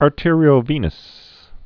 (är-tîrē-ō-vēnəs)